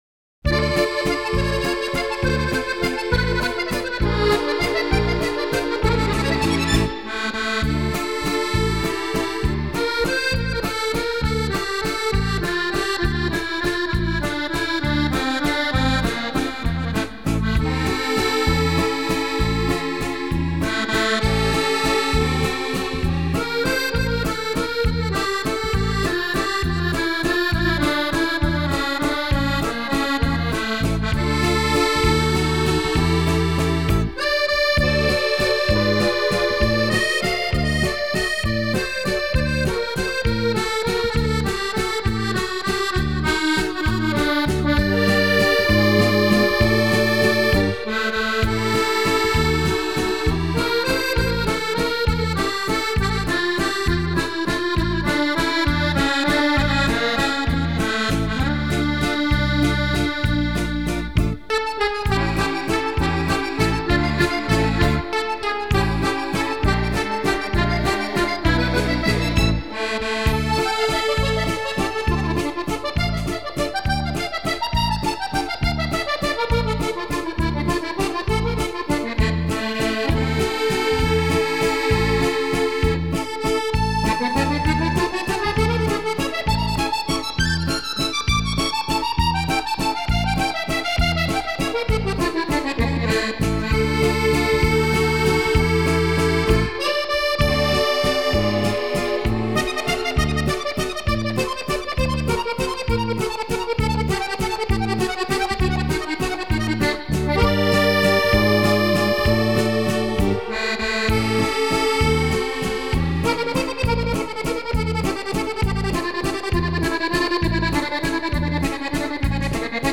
французский аккордеон
francuzskiq-akkordeon.mp3